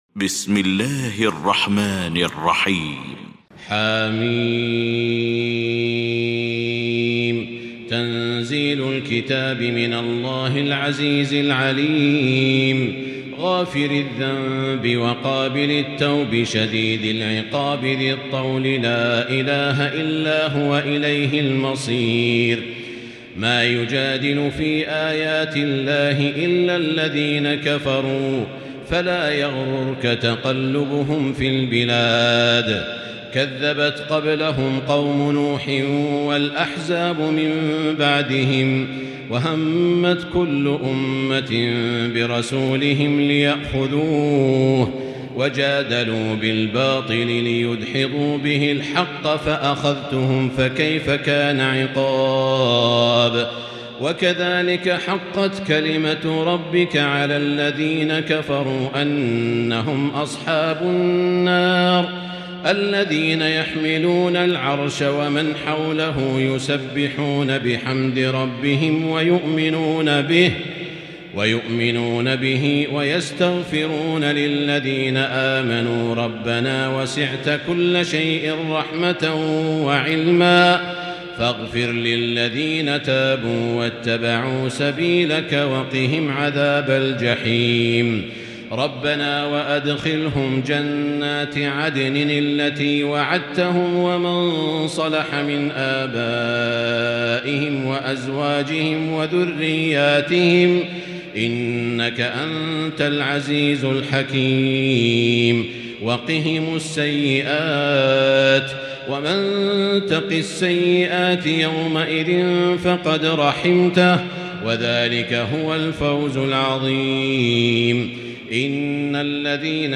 المكان: المسجد الحرام الشيخ: سعود الشريم سعود الشريم فضيلة الشيخ ماهر المعيقلي غافر